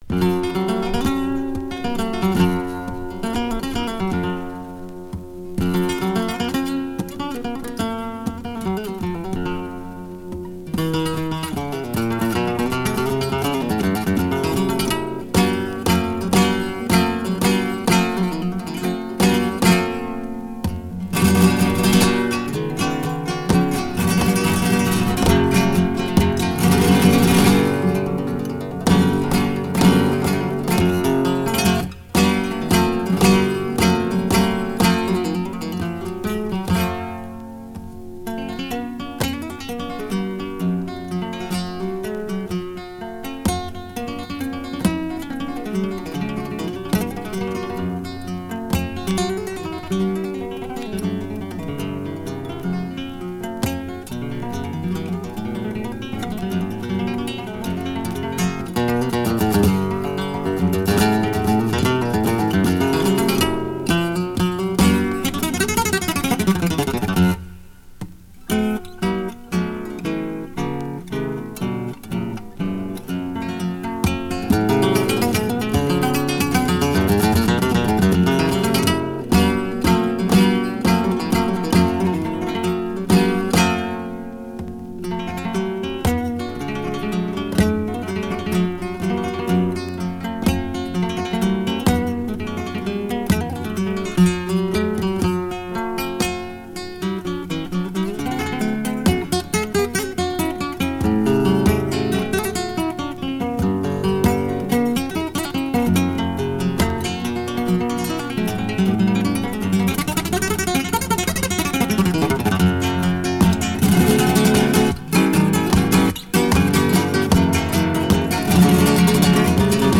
soleá